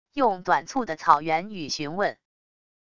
用短促的草原语询问wav音频